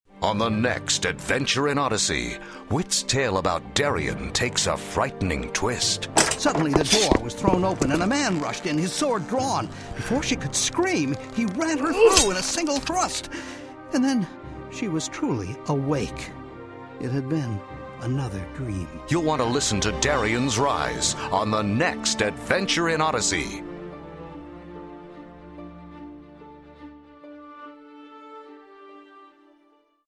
Notes: This audio dramatization is based on Darien's Rise from the Adventures in Odyssey Passages book series.